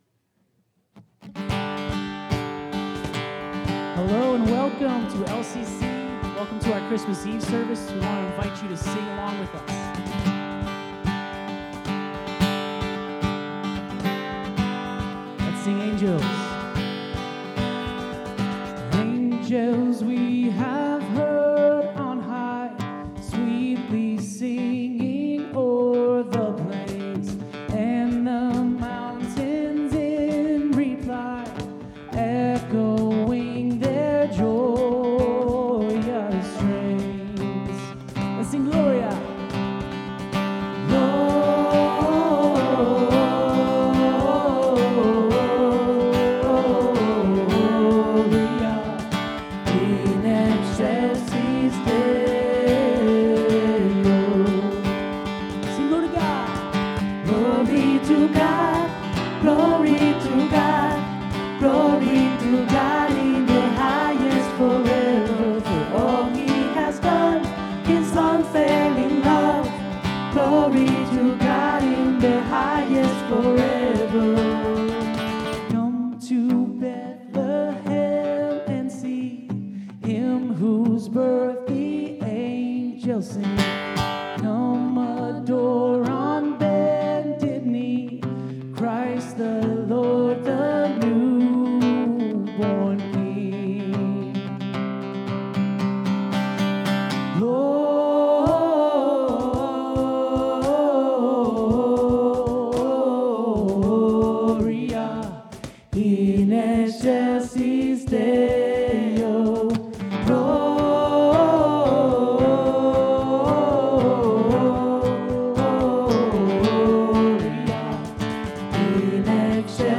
Christmas Eve Service